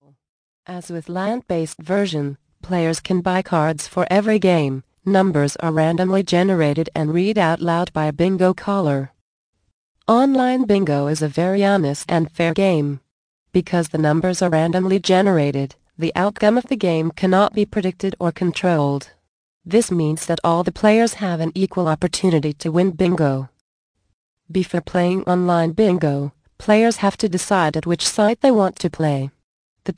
Bingo Winning Secrets. Audio Book. Vol. 4 of 7. 53 min.